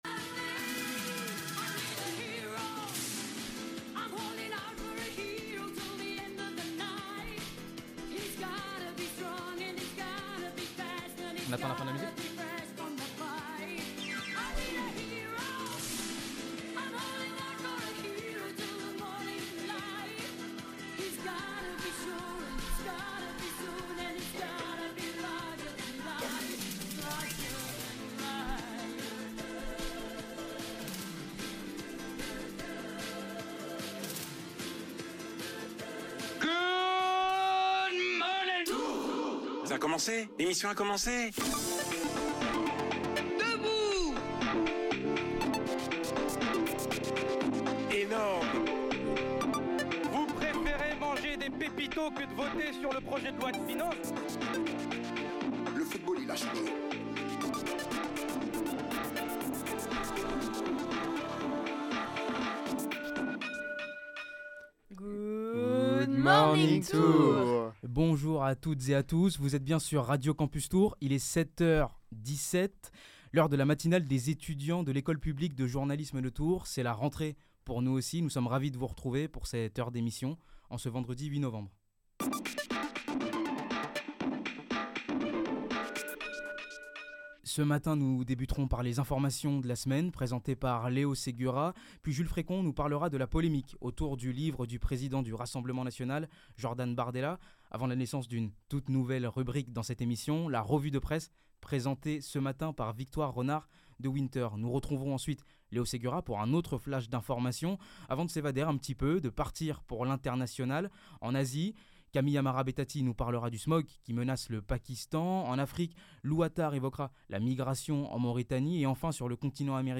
La matinale des étudiants de l’école publique de journalisme de Tours, le vendredi de 7h15 à 8h15.